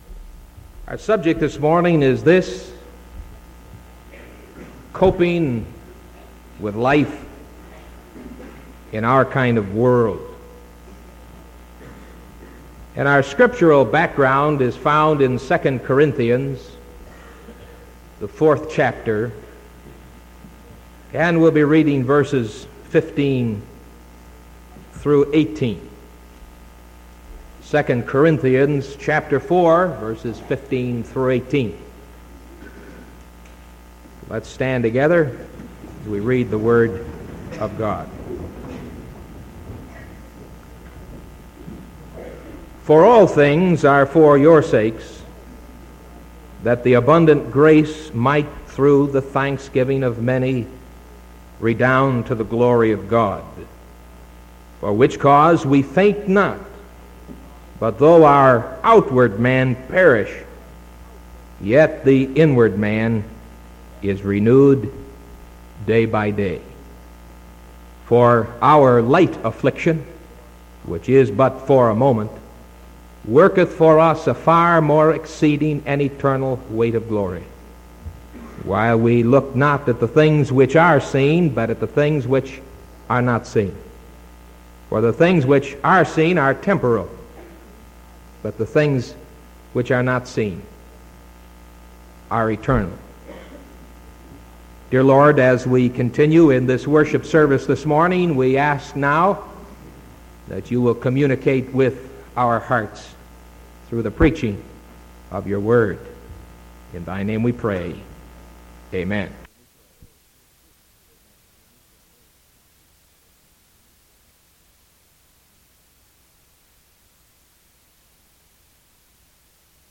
Sermon January 26th 1975 AM